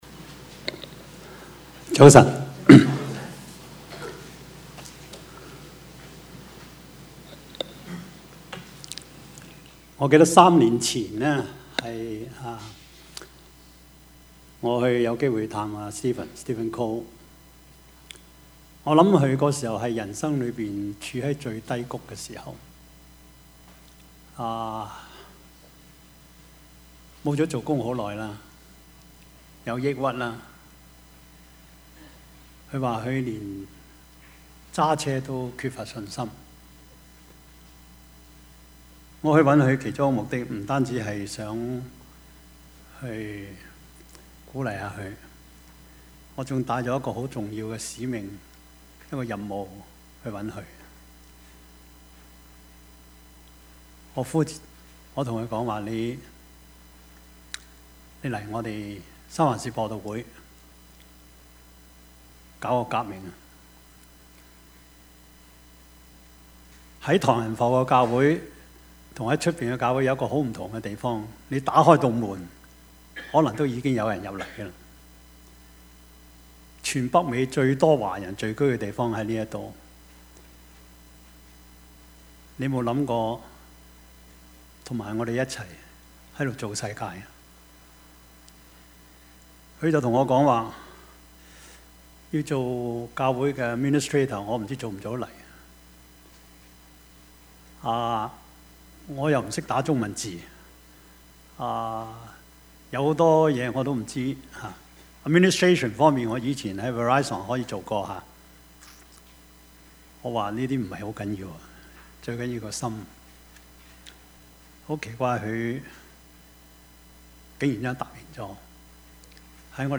Service Type: 主日崇拜
Topics: 主日證道 « 歷史的一刻 這道離你不遠 »